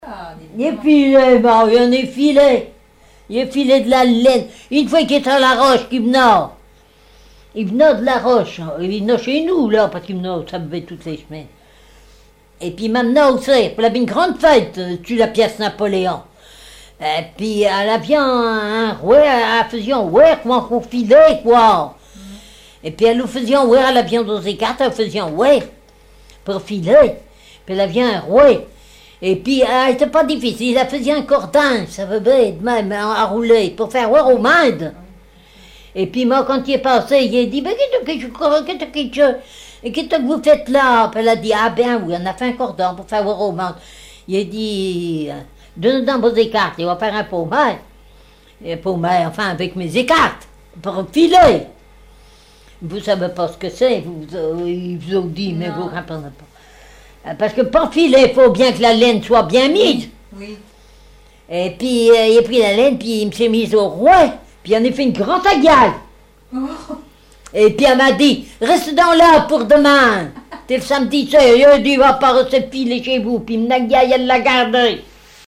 Patois local
Catégorie Témoignage